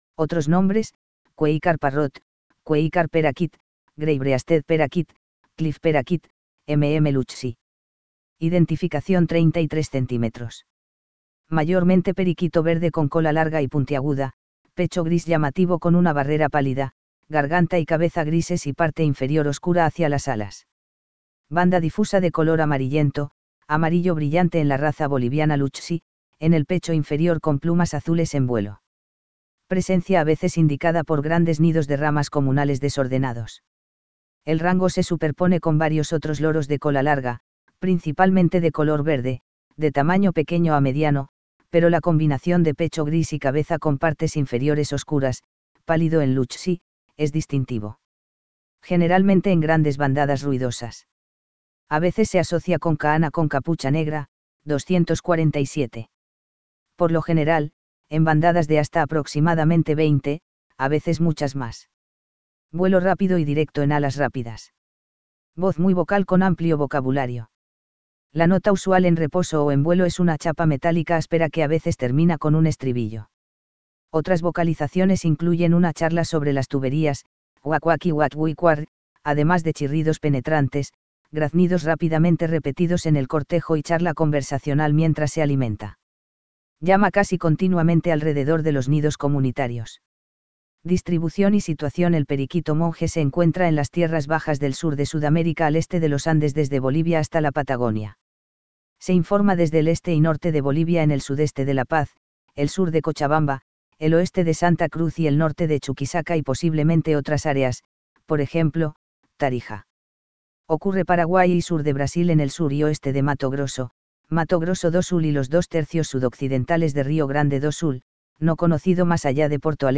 VOZ Muy vocal con amplio vocabulario. La nota usual en reposo o en vuelo es una chapa metálica áspera que a veces termina con un estribillo. Otras vocalizaciones incluyen una charla sobre las tuberías, quak quaki quak-wi quarr, además de chirridos penetrantes, graznidos rápidamente repetidos en el cortejo y charla conversacional mientras se alimenta.